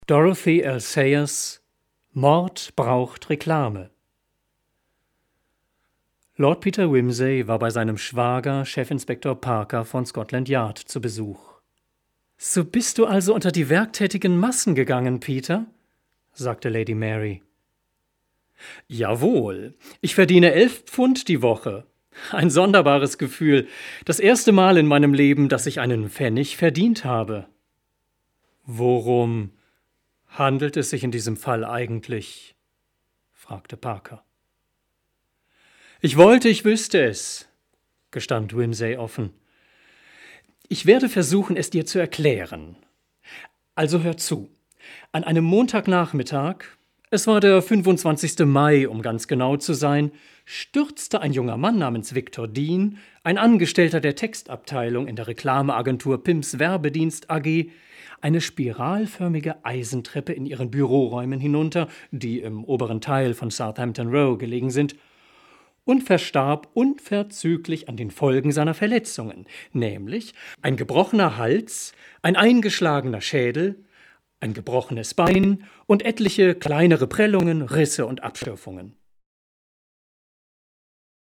Deutscher Sprecher, Literaturlesungen, Kulturmoderation, Bariton
Sprechprobe: Werbung (Muttersprache):
Native German narrator for literature, readings, and presentation of cultural programmes